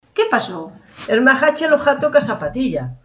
/g/ (/x/ en zonas con gheada)
Nas zonas nas que está ausente existe o /x/ fonema oclusivo farínxeo xordo, que coñecemos como "gheada": ghato, ghaliña.